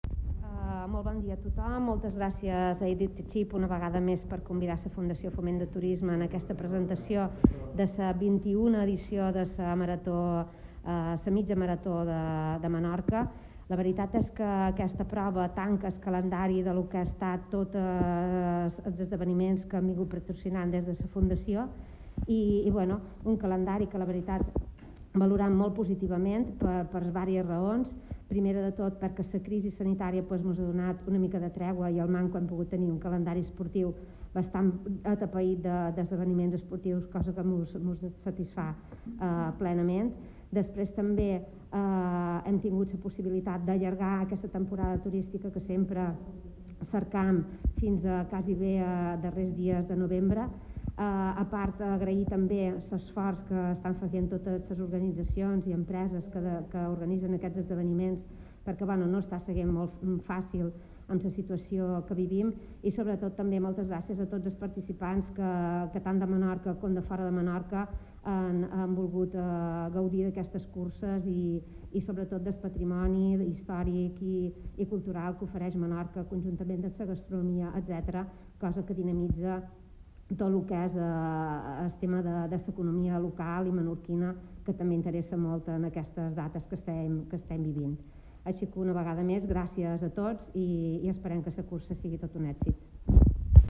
La carrera se ha presentado este miércoles en el mismo ayuntamiento de Ciutadella